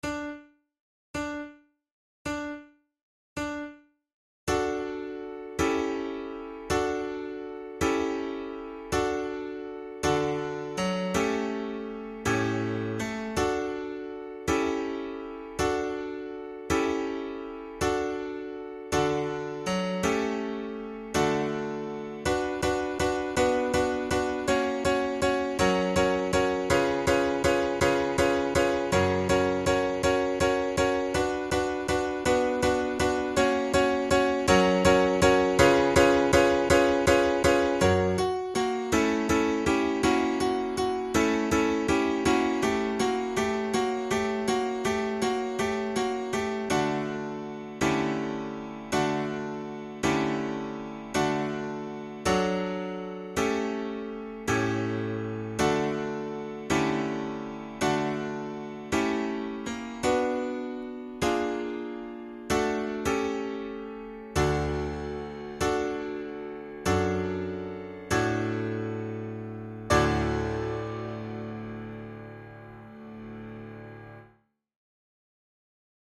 InstrumentationFlute and Piano
KeyD major
Time signature6/8
Tempo54 BPM
Romantic, Written for Flute
by Jules Demersseman, for flute and piano